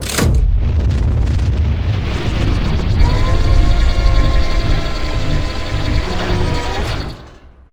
railgun.wav